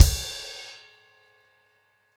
Framework-110BPM_1.5.wav